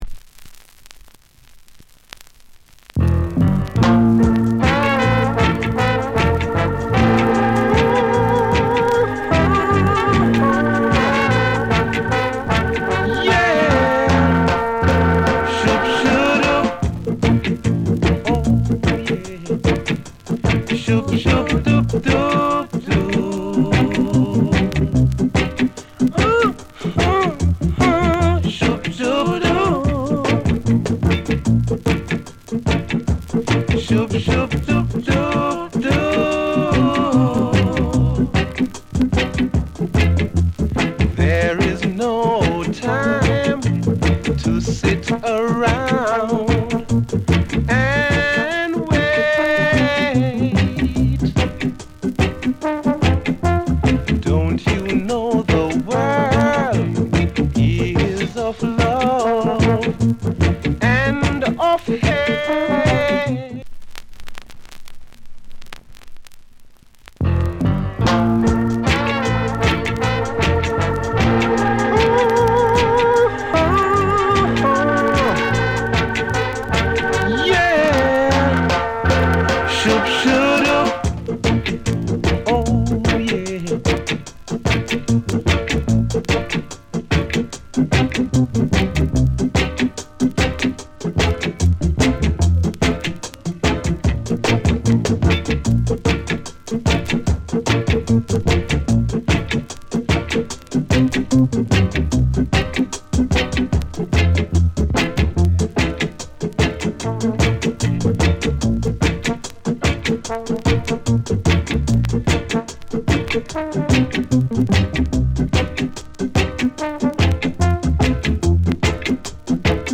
** ジャマイカ盤の再発盤特有のノイズあります。